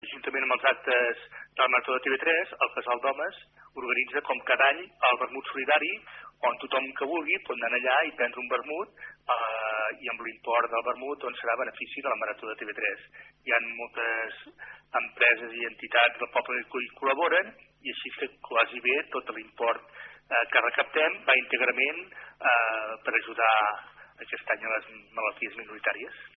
En parla un dels membres d’aquesta entitat